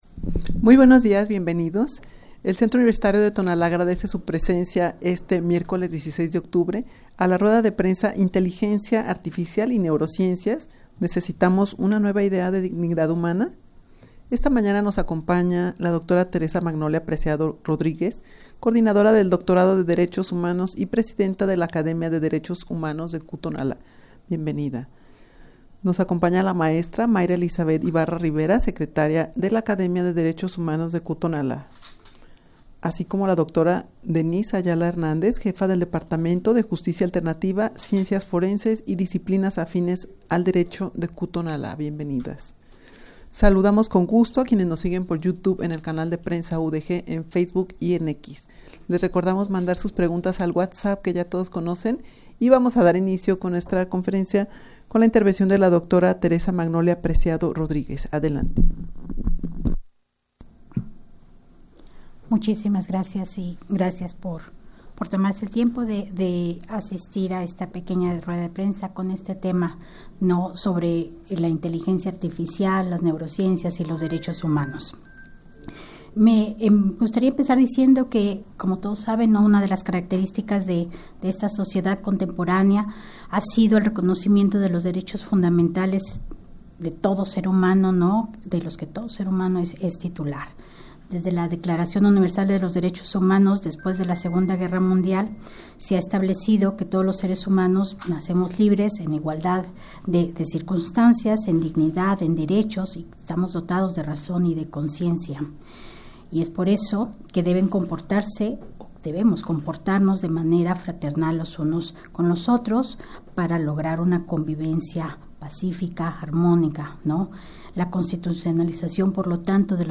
Audio de la Rueda de Prensa
rueda-de-prensa-inteligencia-artificial-y-neurociencias-necesitamos-una-nueva-idea-de-dignidad-humana.mp3